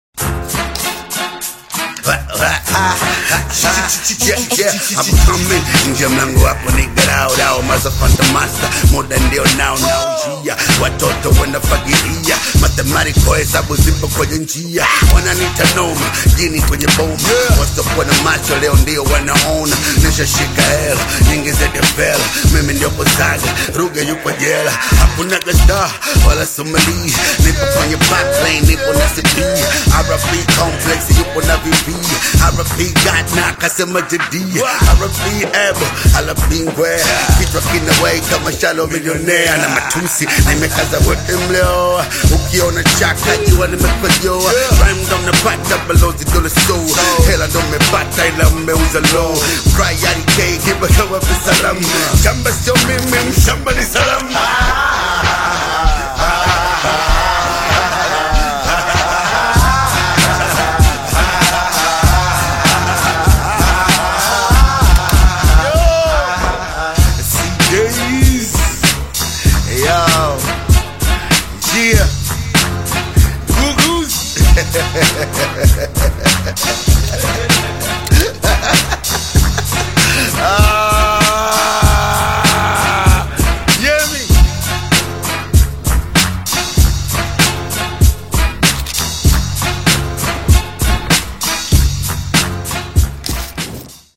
high-energy anthem